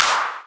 edm-clap-34.wav